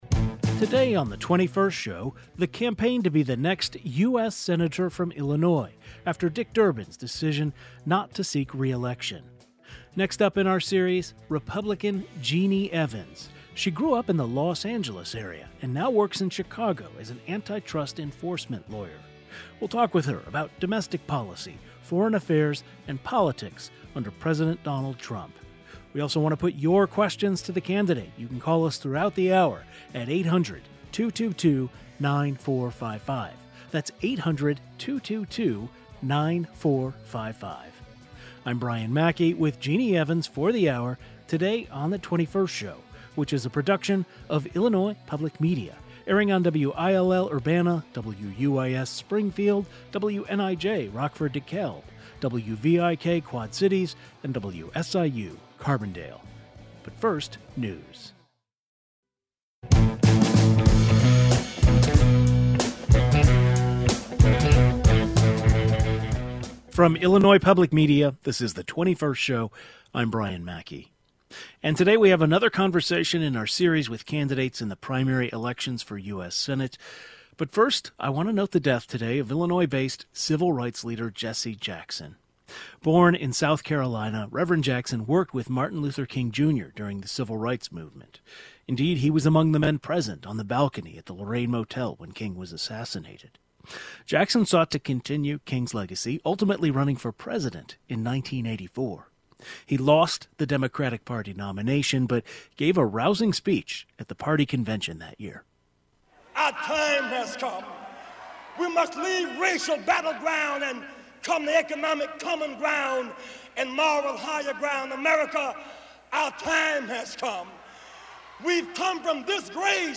We continue our series of conversation with Senate candidates vying to take over outgoing Senator Dick Durbin's set.